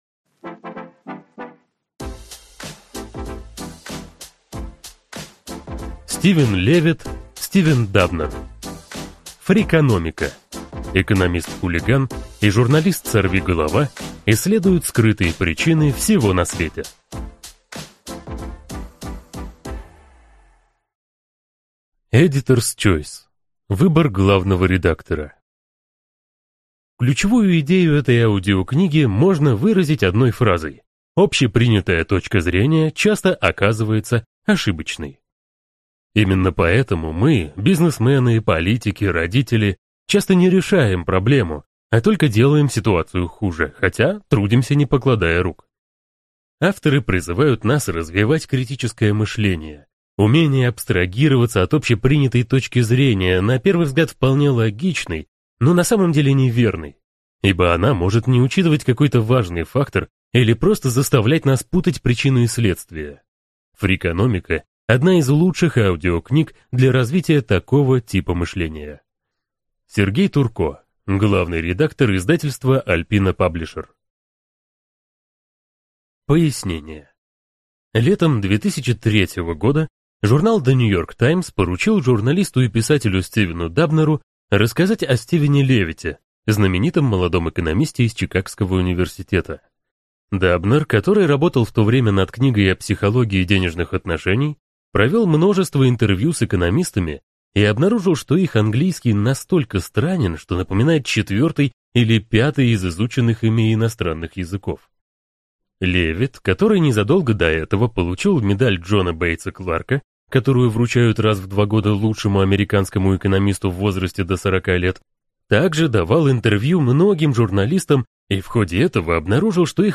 Аудиокнига Фрикономика: Экономист-хулиган и журналист-сорвиголова исследуют скрытые причины всего на свете | Библиотека аудиокниг